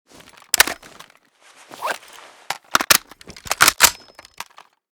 val_reload_empty.ogg.bak